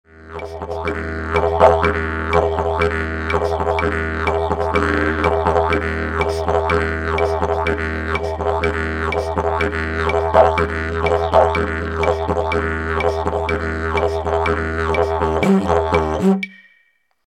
Practice Rhythms to Develop Your Technique
CLICK HERE fast retroflex tongue exercise